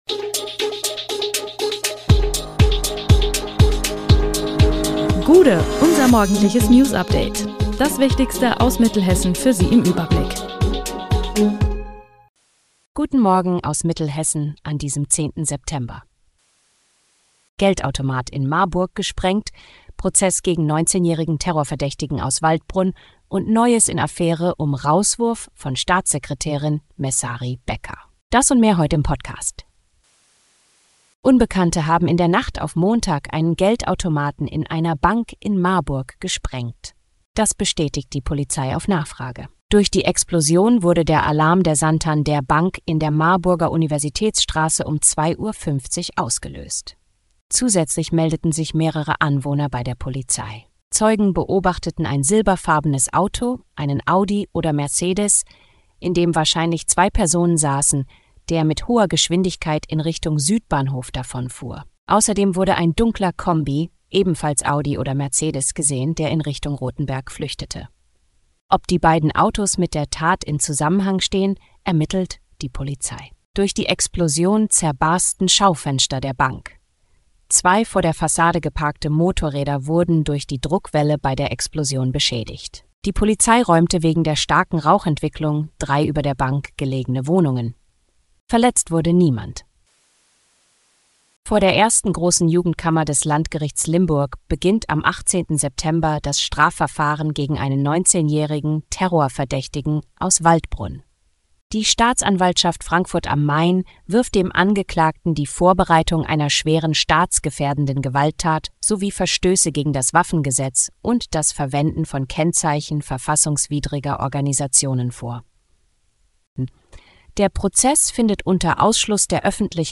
Der Podcast am Morgen für die Region!
Nachrichten